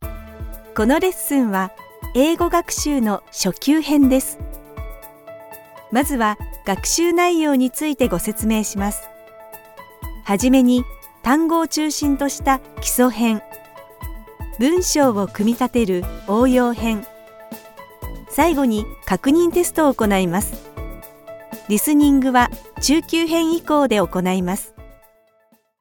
Comercial, Natural, Seguro, Cálida, Empresarial
Explicador
personable, persuasive, versatile, warm and authentic